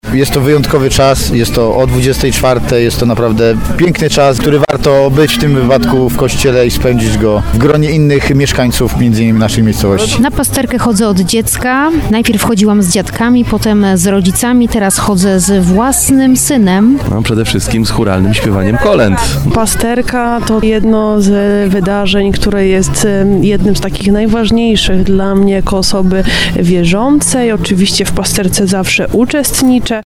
23sonda-pasterka.mp3